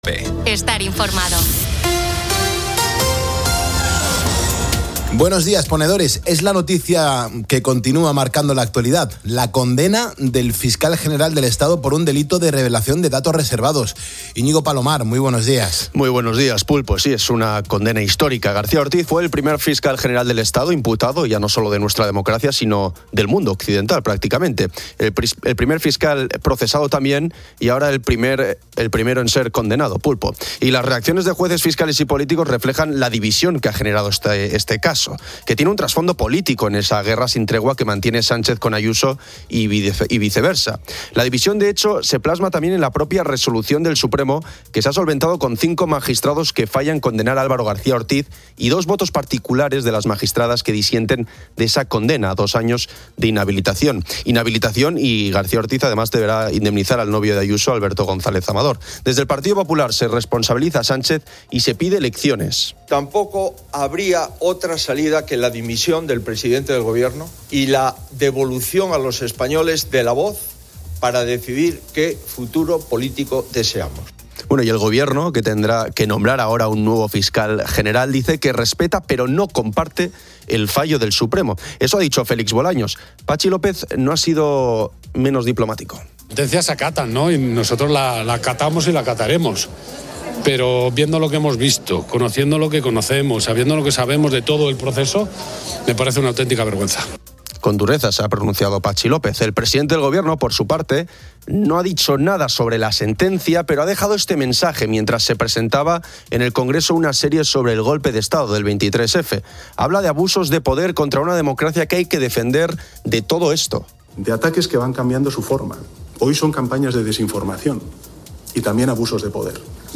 En COPE, los oyentes comparten sus experiencias con la lotería, especialmente la de Navidad, destacando la emoción y las anécdotas de ganar o participar.